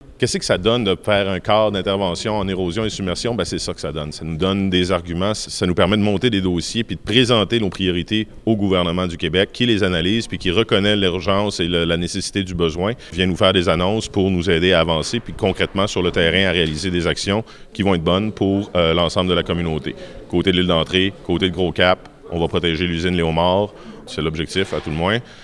Selon le maire des Îles, Antonin Valiquette, l’annonce est un pas important:
Une conférence de presse était organisée à la salle de la mairie par le ministère des Affaires municipales et de l’Habitation pour annoncer les subventions.